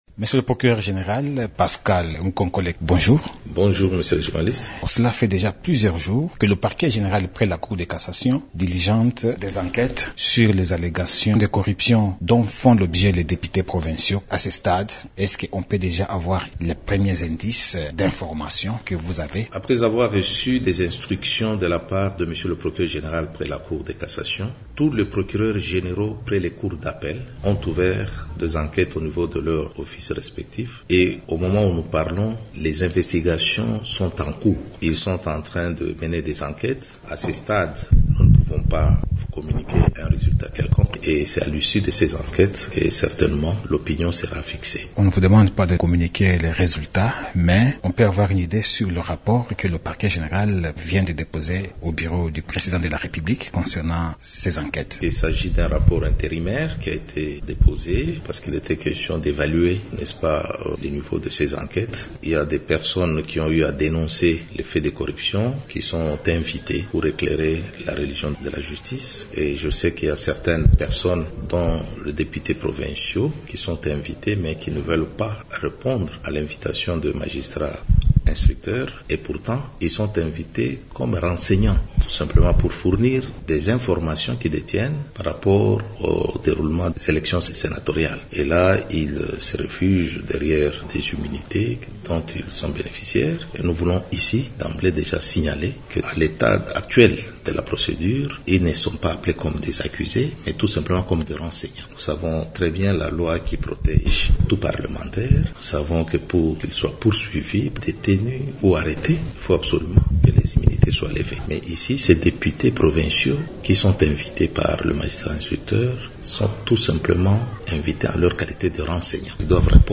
Pascal Mukonkole Katambwe s’entretient